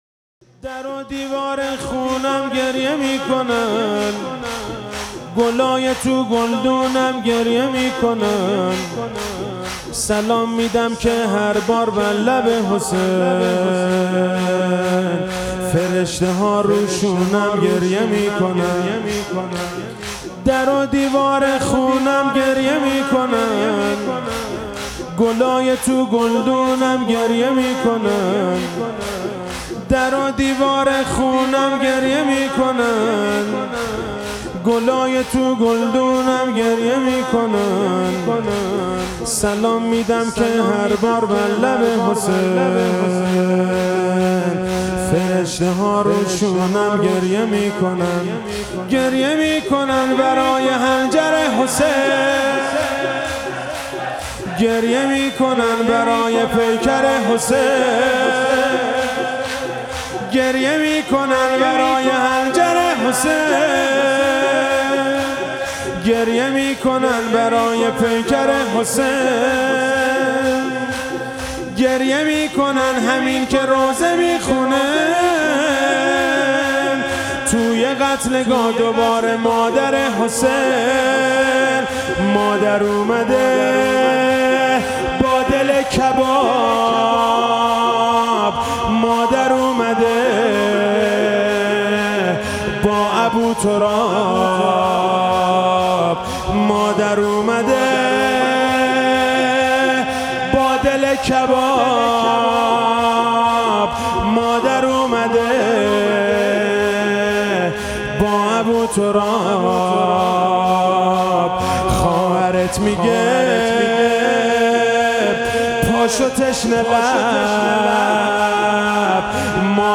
فاطمیه97 روایت 95 روز - شب سوم - شور - در و دیوار خونم گریه میکنند